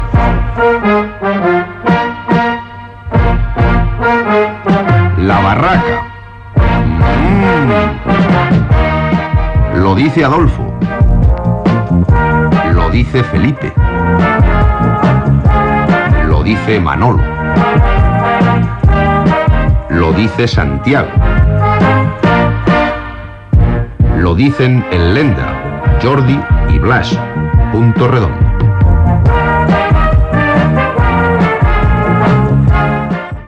Careta d'entrada del programa